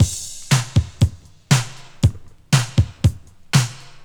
• 119 Bpm Rock Breakbeat Sample F# Key.wav
Free drum groove - kick tuned to the F# note. Loudest frequency: 1664Hz
119-bpm-rock-breakbeat-sample-f-sharp-key-6MP.wav